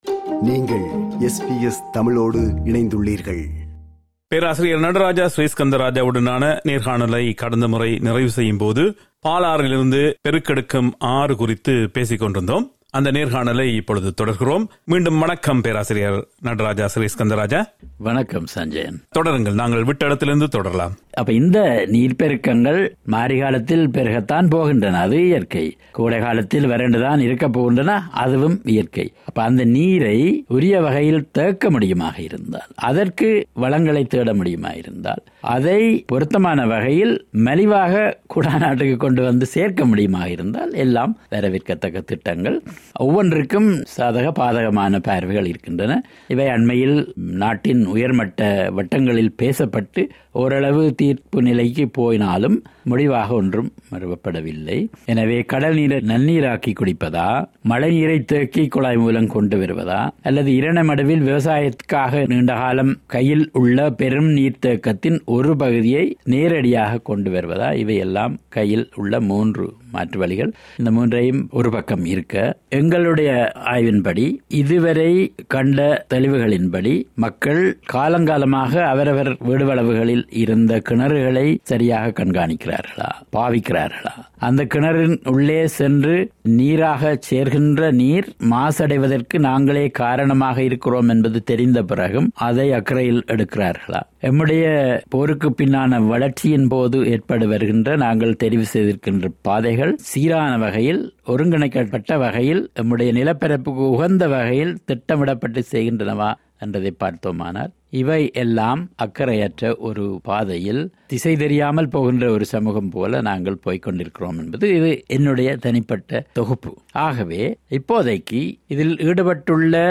நீண்ட நேர்காணலின் நிறைவுப் பகுதி.